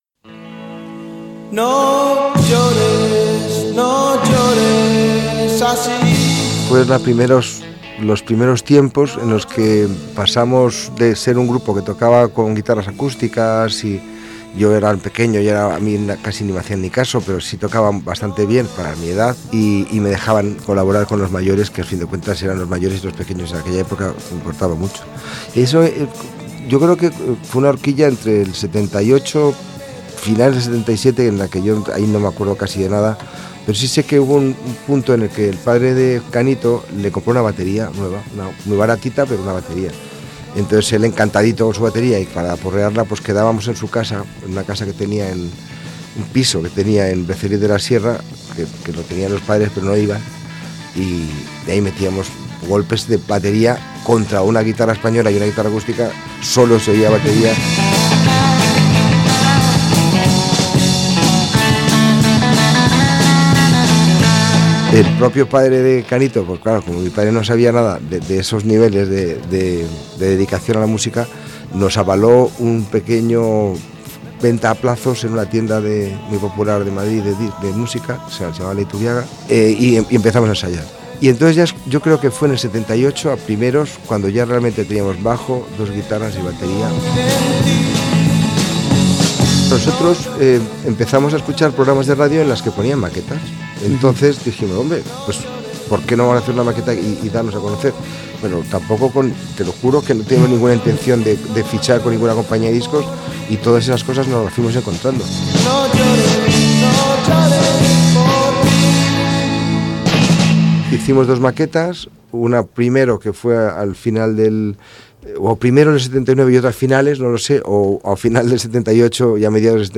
A punto de cumplirse 20 años del fallecimiento de su hermano Enrique, Álvaro Urquijo desvela cómo era la industria discográfica de principios de los 80 y repasa en primera persona la historia de Los Secretos desde la formación inicial con Canito (entonces se llamaban «Tos») y nos «moveremos» con Álvaro hasta la actualidad.